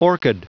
Prononciation du mot orchid en anglais (fichier audio)
Prononciation du mot : orchid